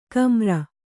♪ kamra